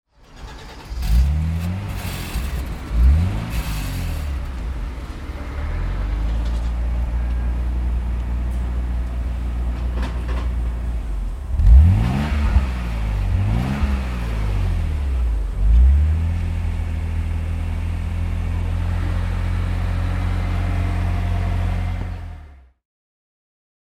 Opel Lotus Omega (1992) - Starten und Leerlauf
Opel_Lotus_Omega_1992.mp3